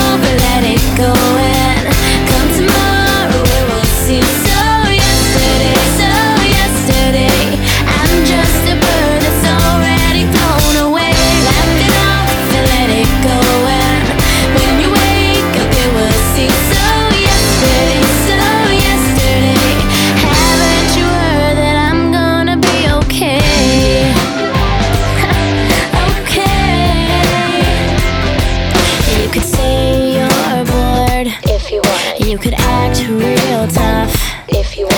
Жанр: Танцевальные / Поп / Рок